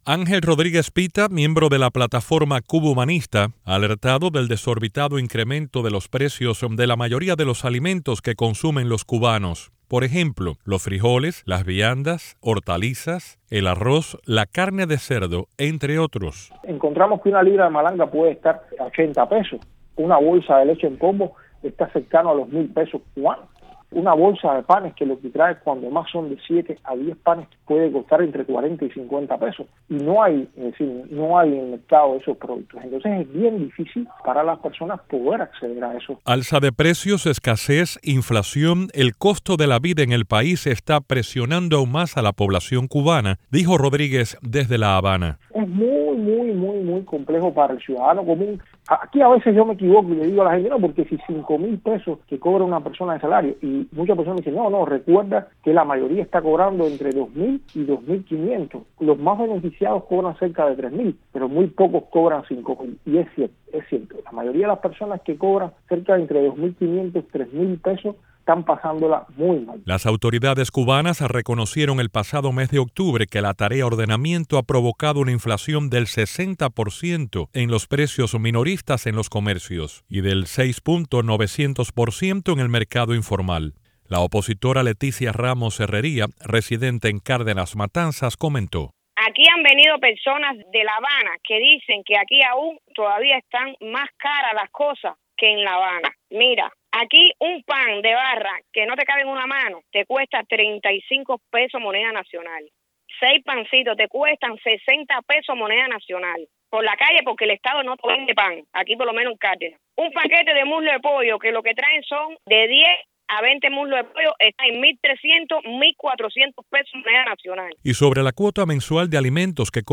El aumento de precios de los alimentos en la isla hace cada vez más difícil la vida diaria de los cubanos, confirman varios entrevistados a Radio Televisión Martí.